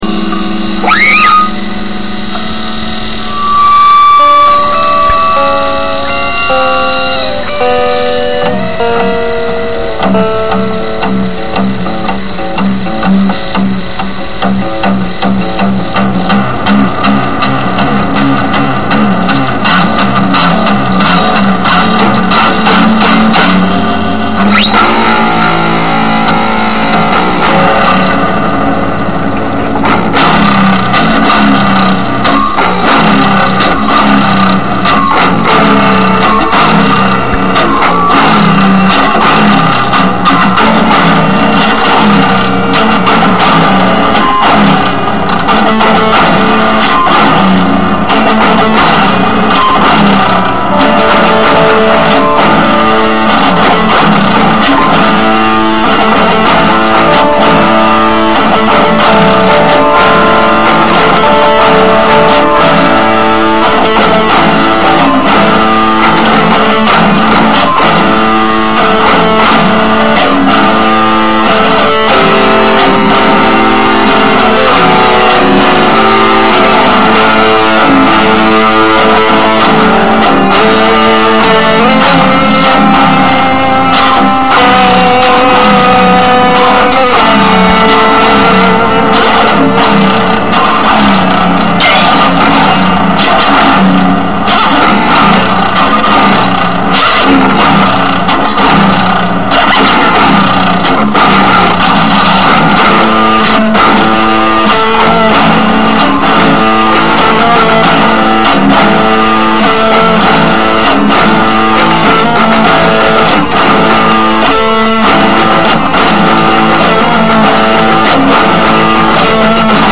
Rock
All sounds made with my trusty guitar.
Recorded in Audacity.
It felt dark though is it supposed to feel that way?